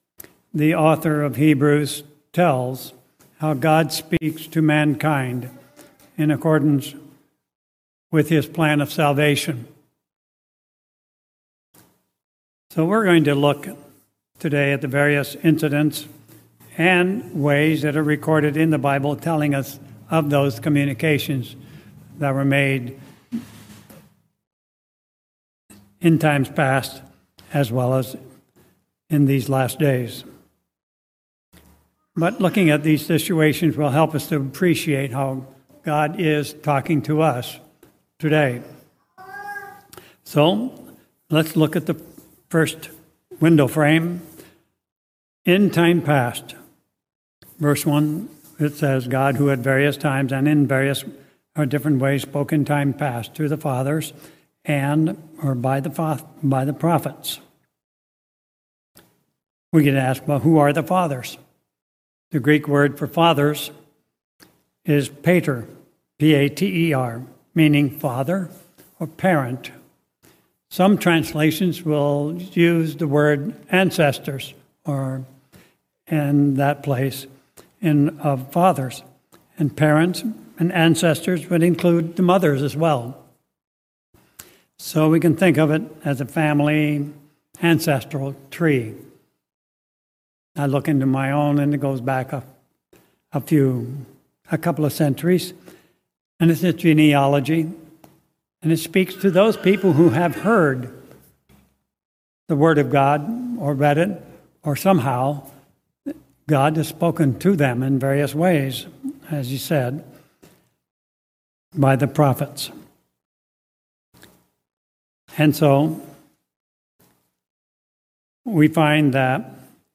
Sermons
Given in Tacoma, WA Olympia, WA